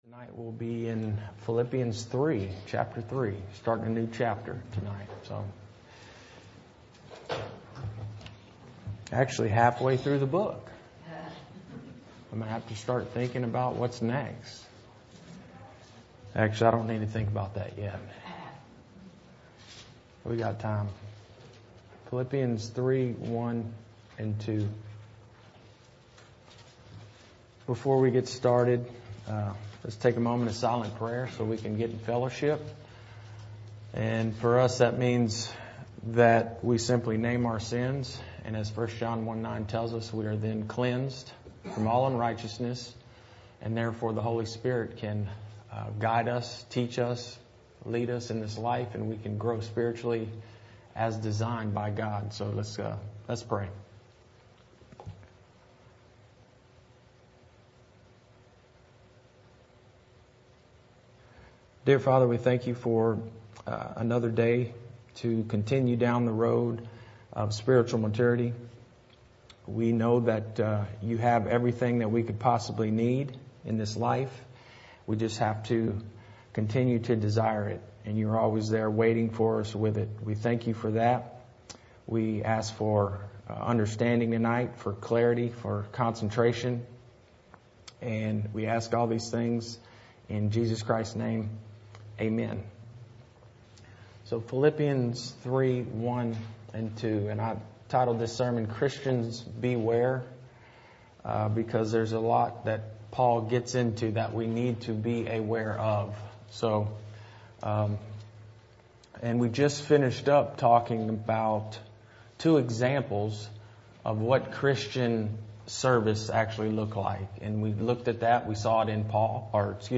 June 2, 2021 – Wednesday Bible Study – "Christians Beware"
Sermon Audio